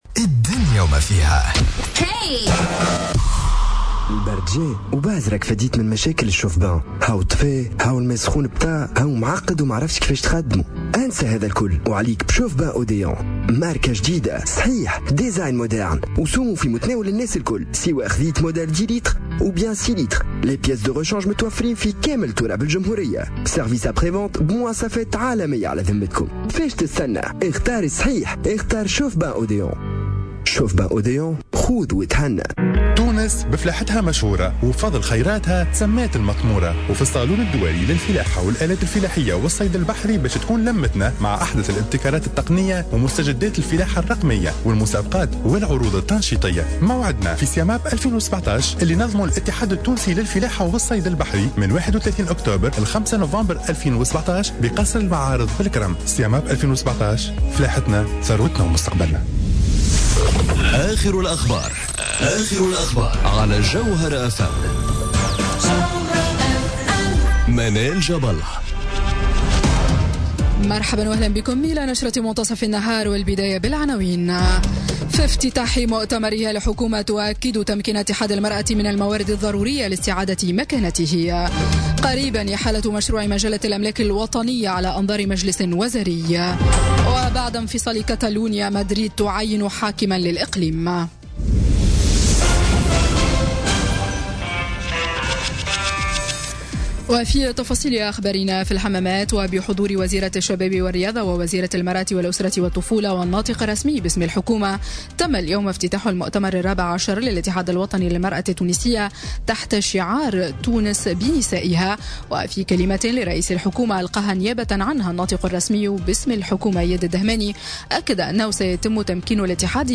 نشرة أخبار منتصف النهار ليوم السبت 28 أكتوبر 2017